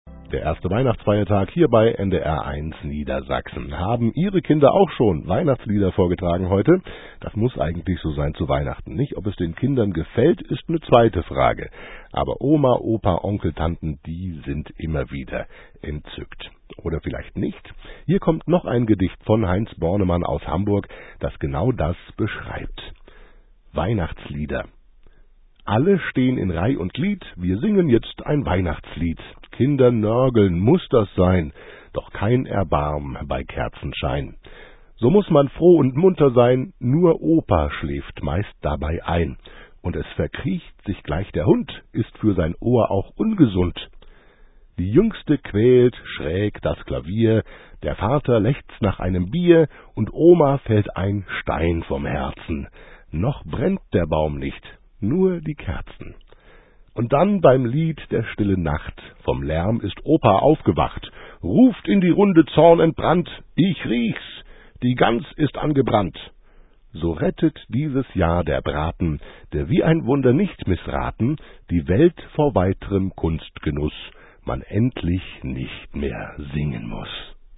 Weihnachtsgedichte
und trug sie vor mit sanftem Ton
las zwischen weihnachtlich Gesang